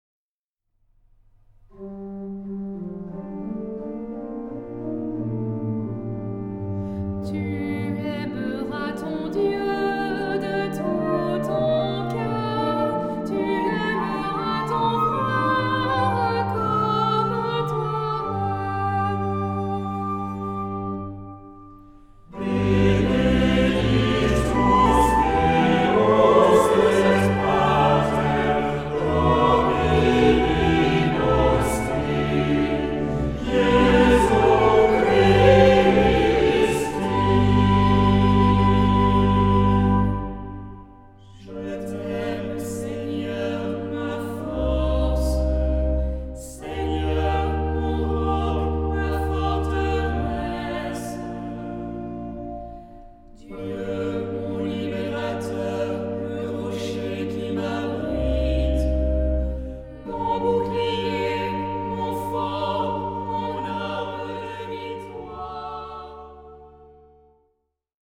Genre-Style-Forme : Tropaire ; Psalmodie
Caractère de la pièce : recueilli
Type de choeur : SAH  (3 voix mixtes )
Instruments : Orgue (1) ; Instrument mélodique (1)
Tonalité : sol majeur ; sol mineur
interprété par Alto solo
Flûte
Quatuor de solistes